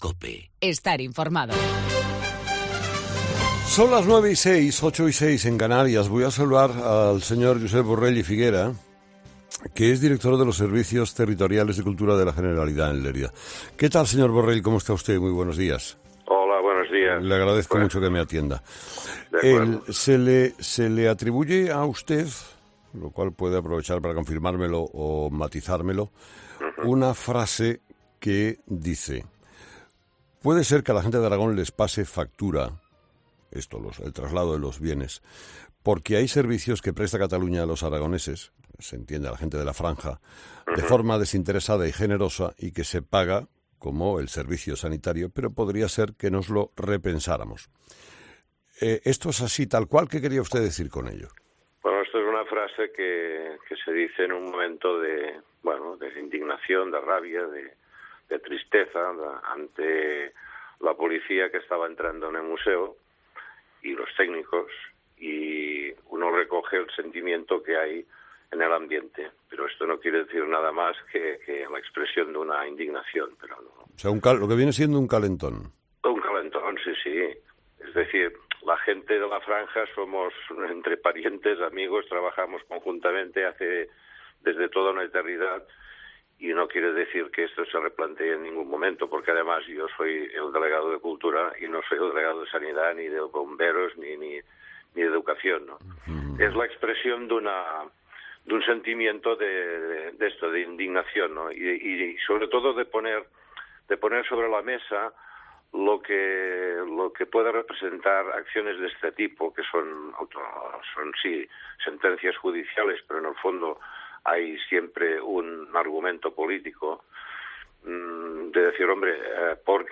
Josep Borrell, delegado de Cultura de la Generalitat en Lleida